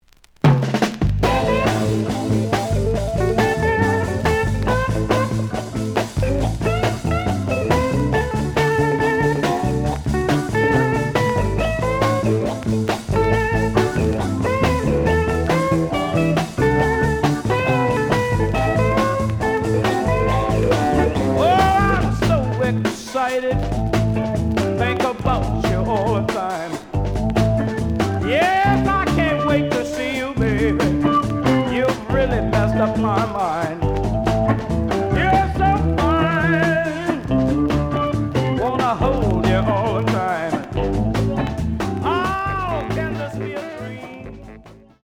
The audio sample is recorded from the actual item.
●Genre: Blues